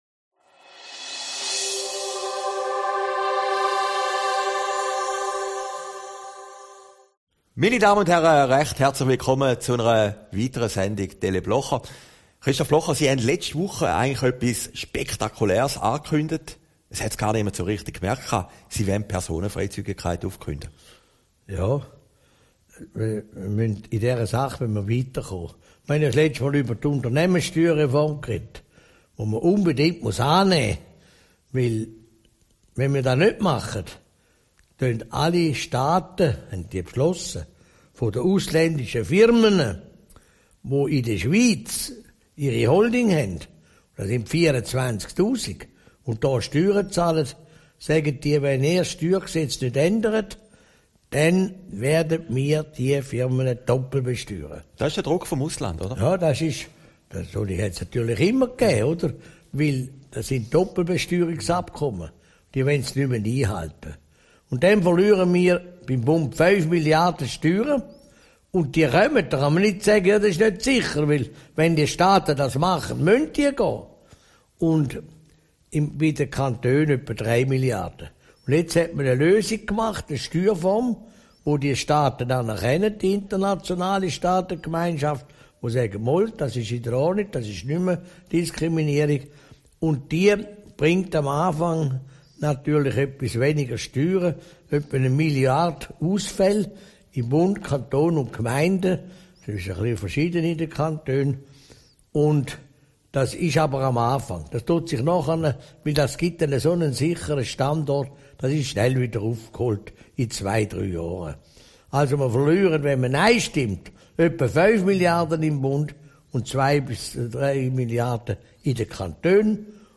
Aufgezeichnet in Herrliberg, 20. Januar 2017